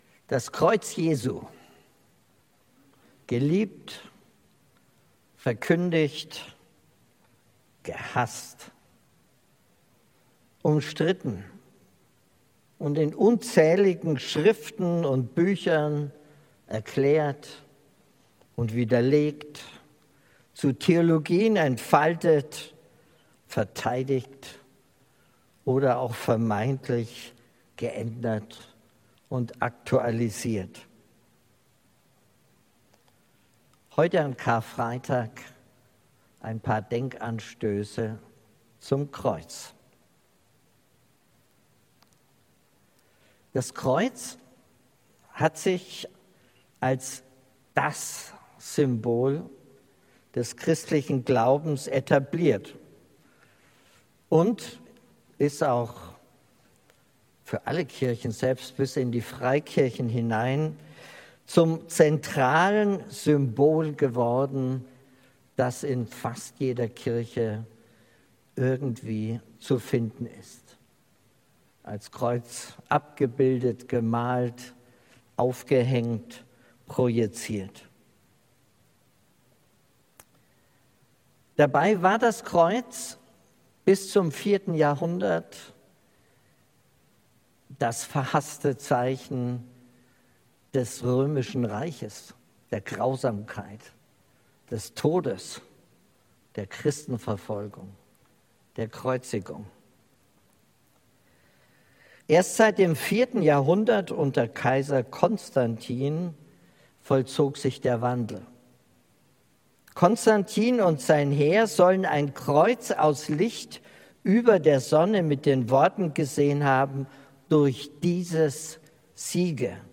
Karfreitagsgottesdienst